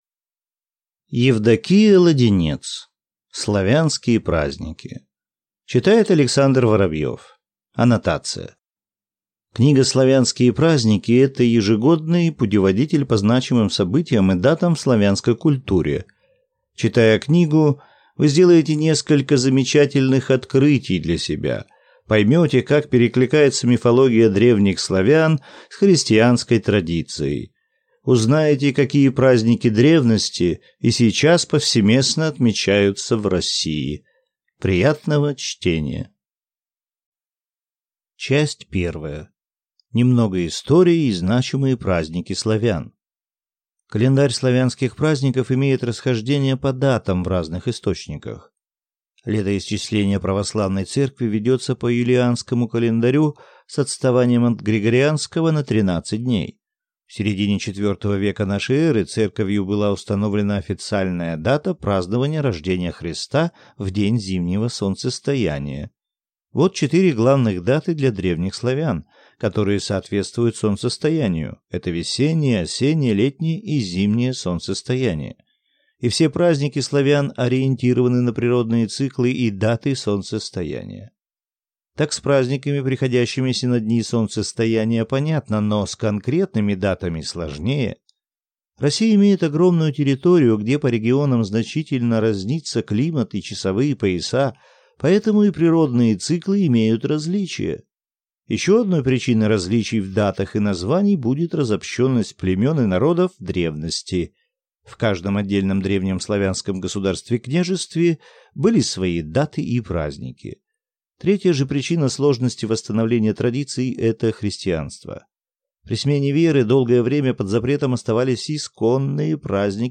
Аудиокнига Славянские праздники | Библиотека аудиокниг
Прослушать и бесплатно скачать фрагмент аудиокниги